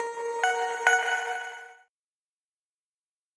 Звуки уведомлений для электронной почты
На этой странице собраны звуки уведомлений для электронной почты — короткие и четкие сигналы о новых письмах.